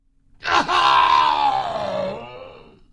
military sounds » Moans and screams of agony of military soldiers
标签： screams painful armee screaming voice passing military passingaway soldier hurt male medic grunt bleeding army dying help pain death scream moan
声道立体声